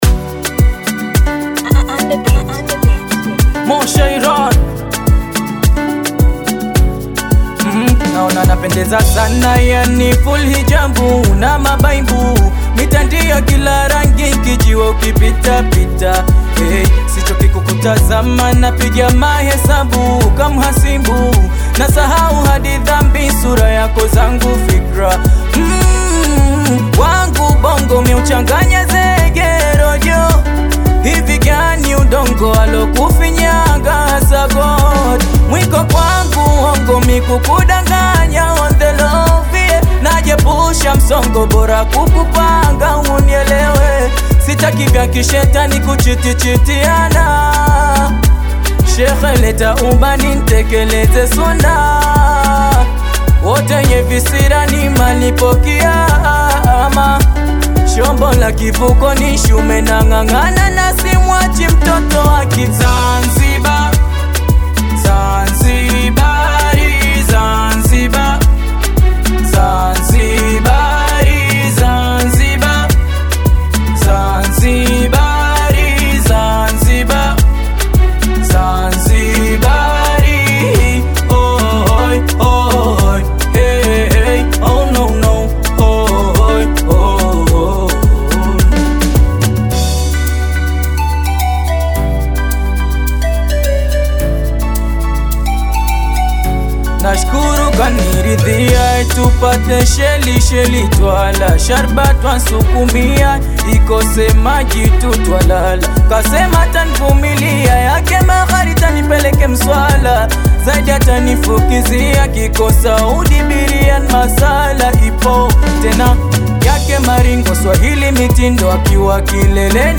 Tanzanian bongo flava artist
African Music